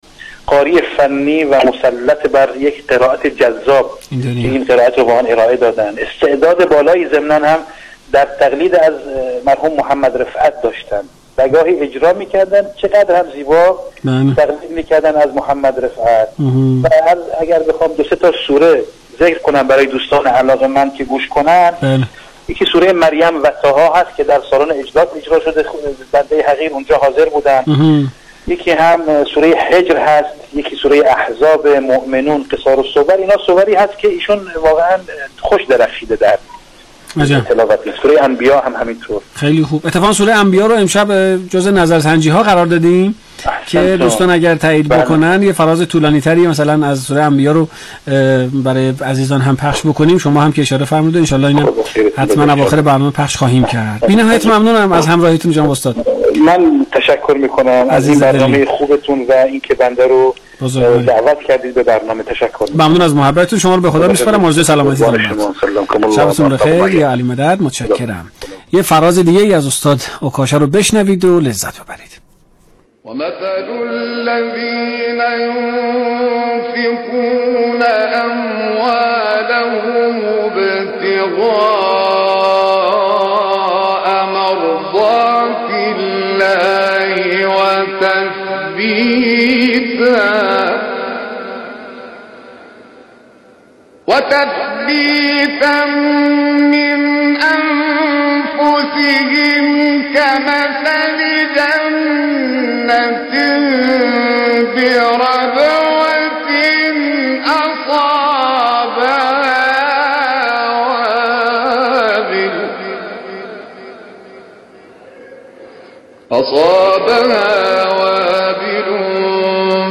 اجراهای غیرقابل پیش‌بینی، اما مبتنی بر معنی